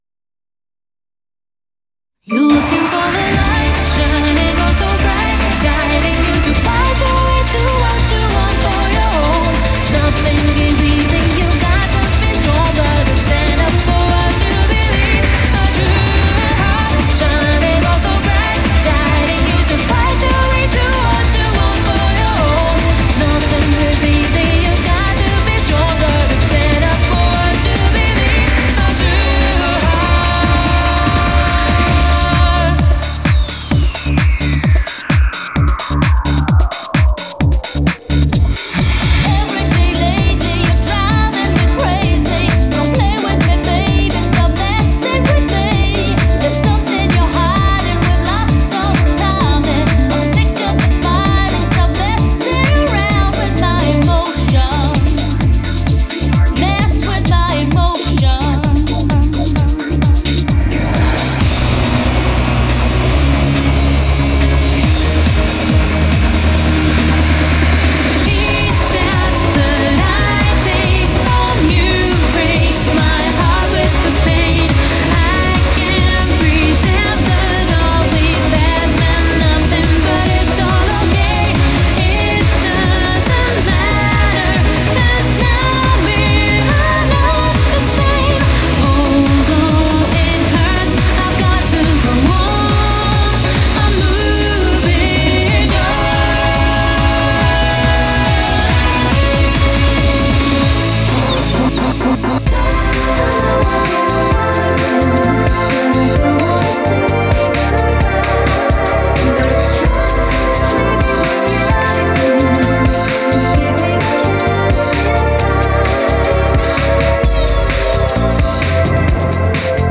BPM120-170
Audio QualityPerfect (High Quality)
CommentsThe order of charts for this megamix are